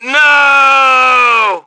1 channel
H_soldier1_102.wav